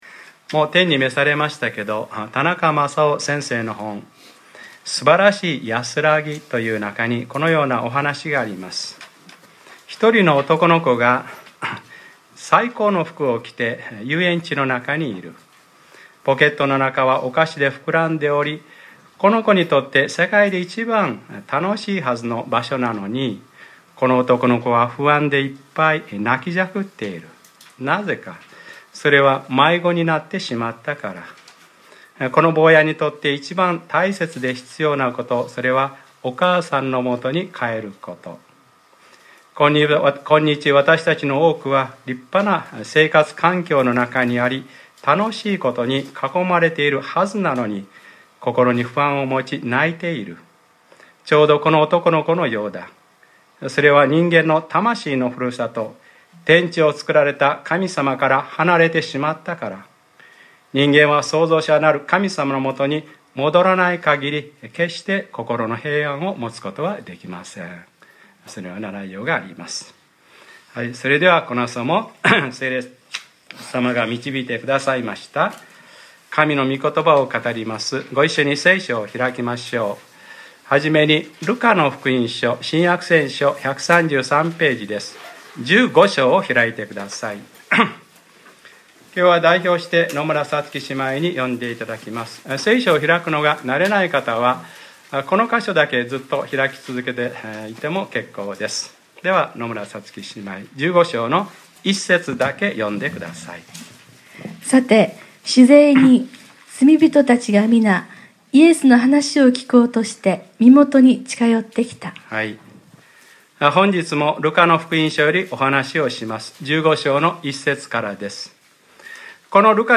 2015年03月08日）礼拝説教 『ルカｰ５６：まさる喜びが天にあるのです』